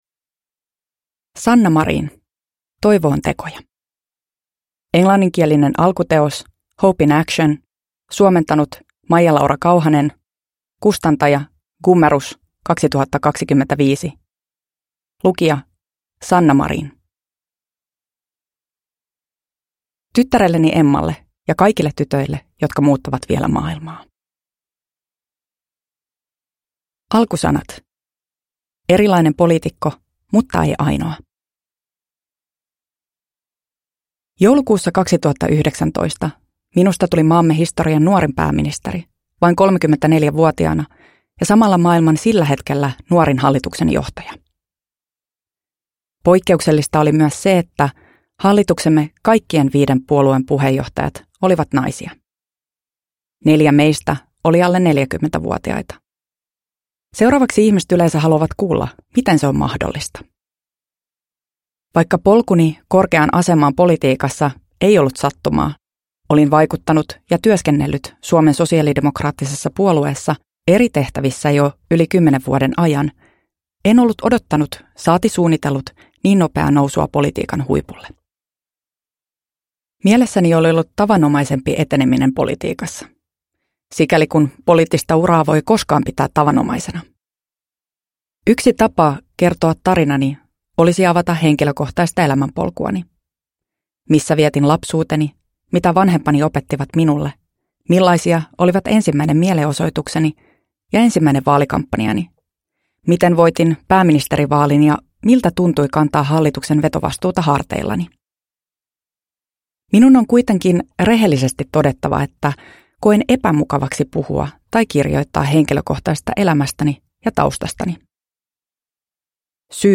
Toivo on tekoja (ljudbok) av Sanna Marin